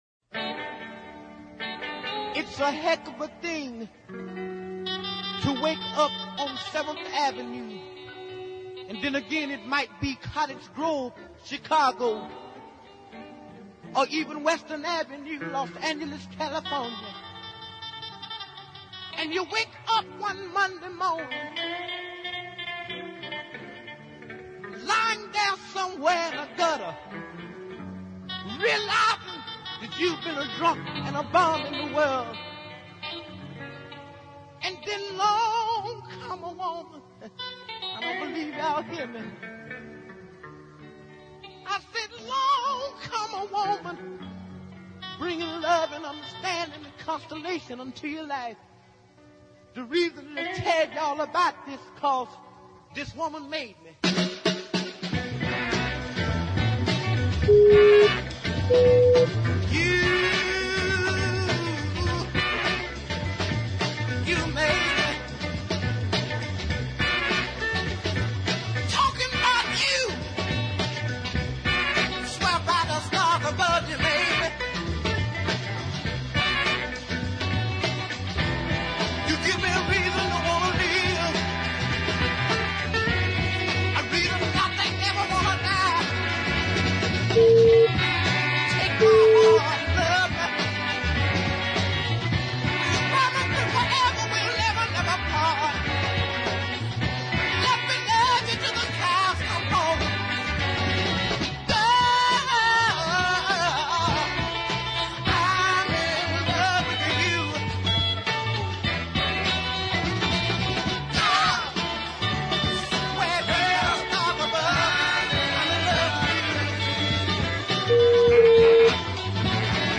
deep soul track
uncompromising attack and screaming vocal artistry